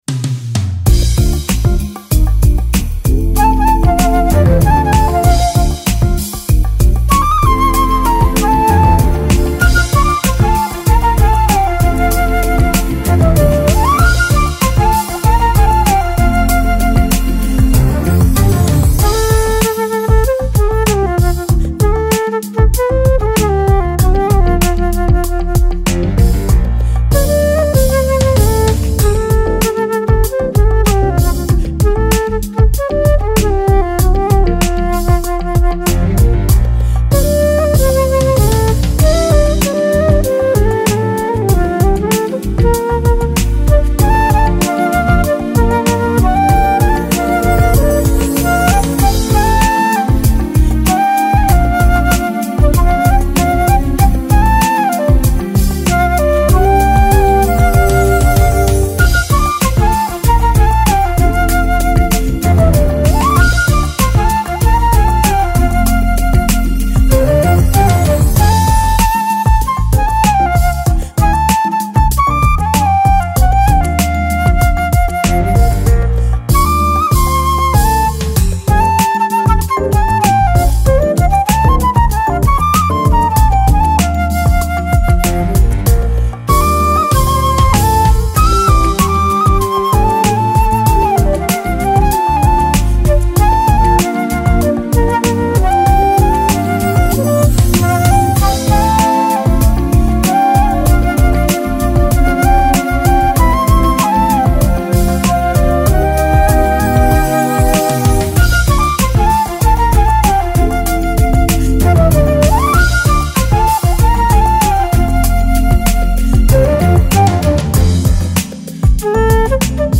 Flute-playing soul-jazz
contemporary jazz flutist/vocalist